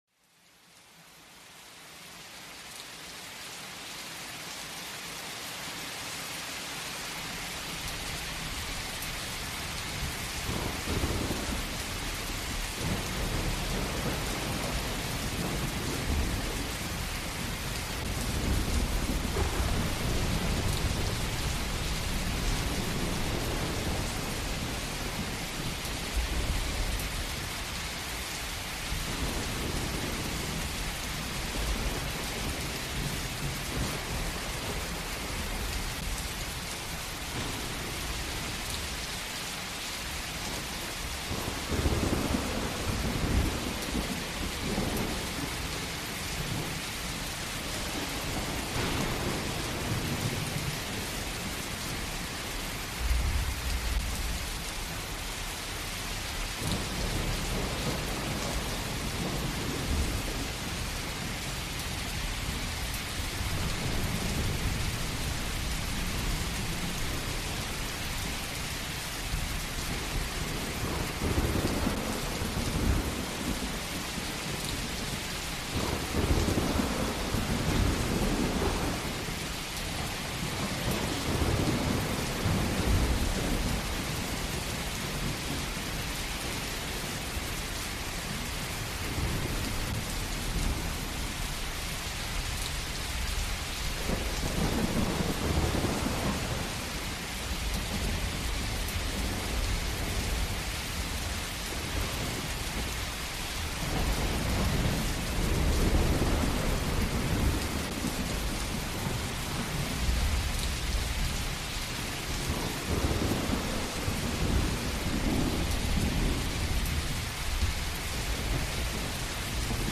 Rainforest Harmony for Deep Relaxation – Relaxing Mind Journey
Each episode of Send Me to Sleep features soothing soundscapes and calming melodies, expertly crafted to melt away the day's tension and invite a peaceful night's rest. Imagine the gentle hum of a distant thunderstorm, the serene flow of a mountain stream, or the soft rustle of leaves in a midnight breeze—sounds that naturally lull you into deep relaxation.